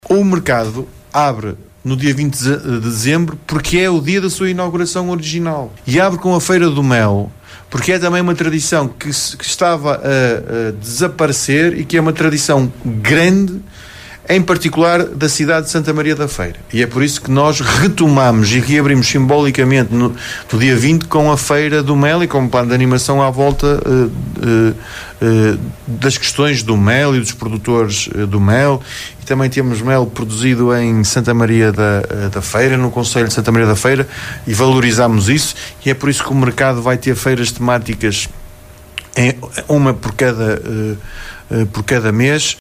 “Abre com Feira do Mel, é também uma tradição que estava a desaparecer e que é uma tradição grande em particular da cidade de Santa Maria da Feira“, apontou o presidente da Câmara da Feira, Amadeu Albergaria, numa entrevista recente à Rádio Sintonia, destacando que há produção do mel também no concelho.